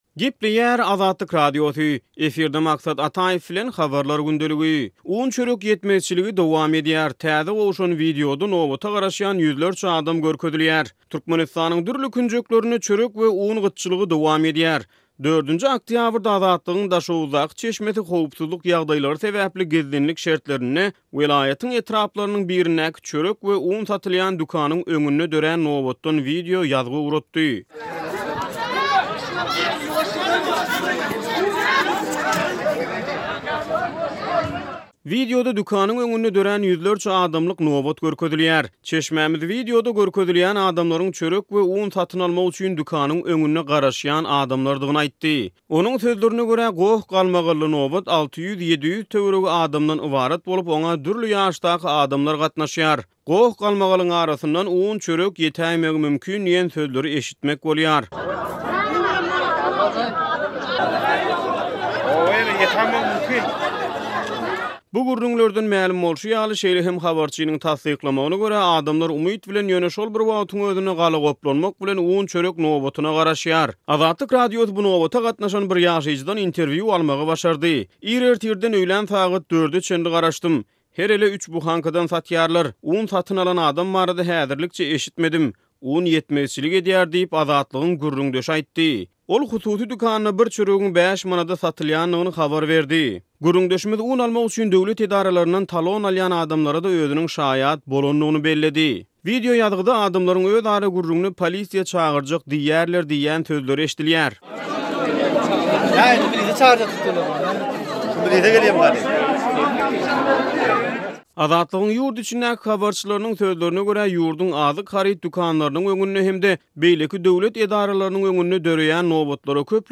Türkmenistanyň dürli künjeklerinde çörek we un gytçylygy dowam edýär. 4-nji oktýabrda Azatlygyň Daşoguzdaky çeşmesi howpsuzlyk ýagdaýlary sebäpli gizlinlik şertlerinde welaýatyň etraplarynyň birindäki çörek we un satylýan dükanyň öňünde döran nobatdan wideo ýazgy ugratdy.